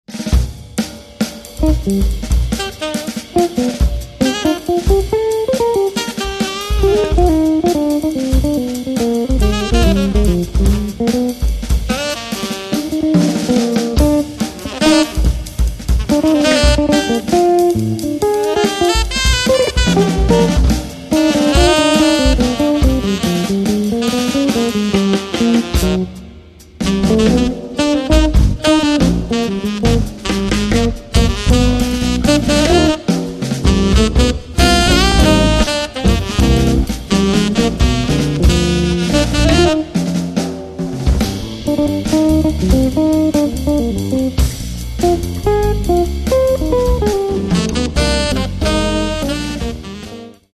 Каталог -> Джаз и около -> Сборники, Джемы & Live
guitar
tenor sax
el. bass
drums